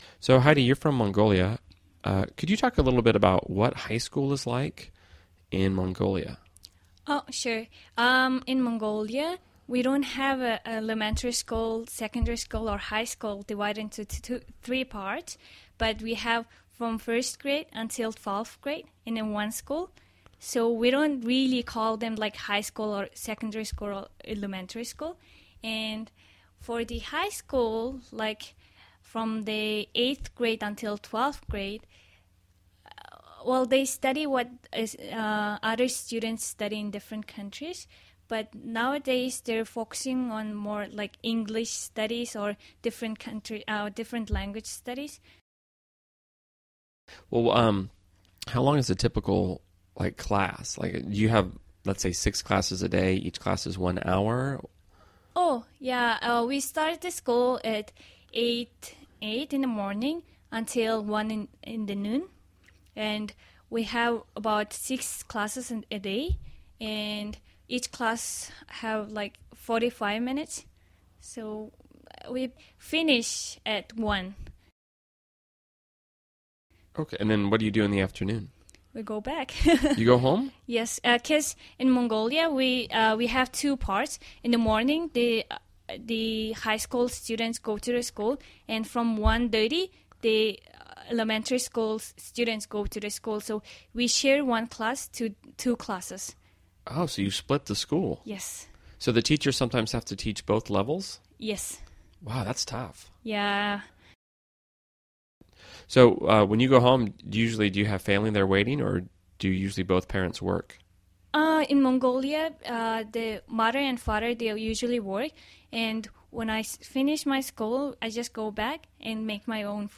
英语初级口语对话正常语速14：蒙古学校（mp3+lrc）